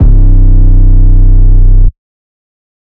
808 (Grammys).wav